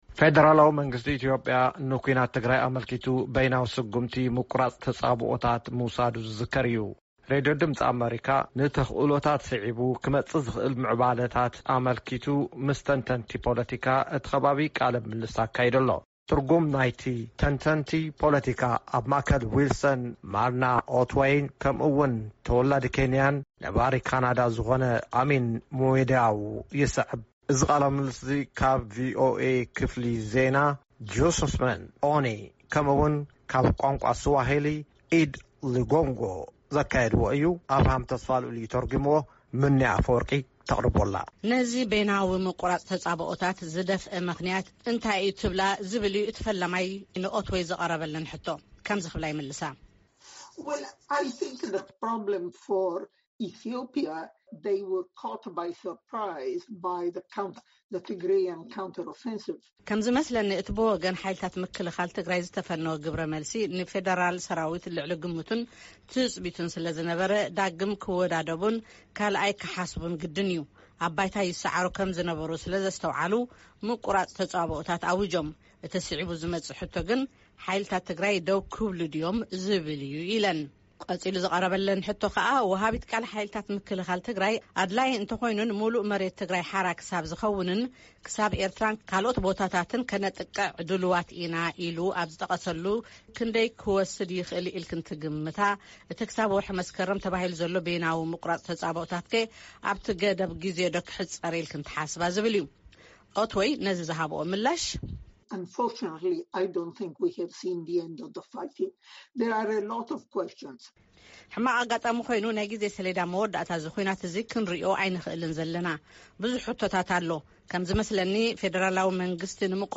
ረድዮ ድምጺ ኣመሪካ ንተኽእሎታትን ስዒቡ ክመጽእ ዝኽእል ምዕባለታትን ኣመልኪታ ምስ ተንተንቲ ፖለቲካ እቲከባቢ ቃለ-ምልልስ ገይራ ኣላ።